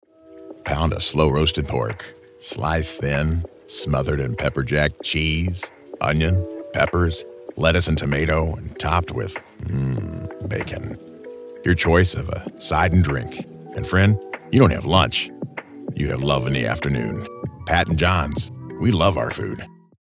Young Adult
Middle Aged
Pat and John's-Commercial-Deep-Gritty-Conversational.mp3